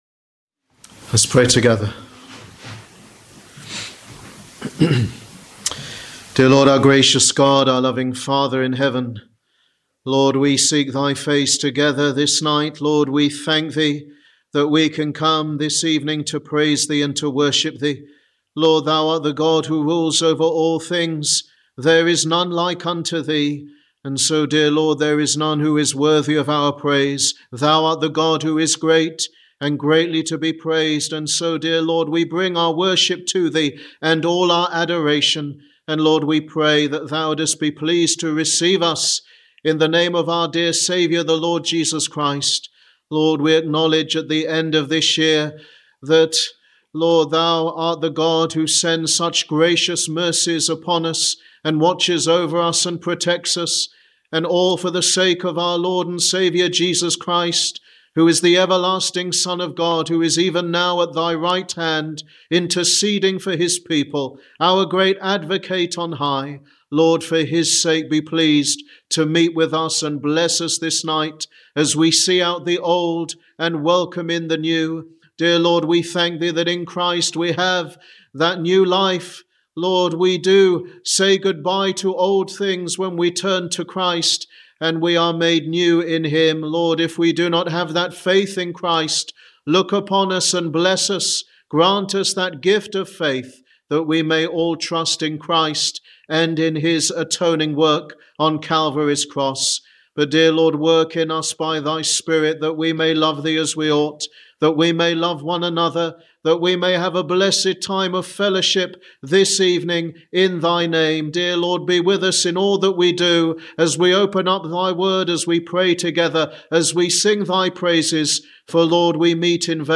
Sermon: The Alpha and the Omega
EDT New Years Eve Service 2025 Revelation 22.13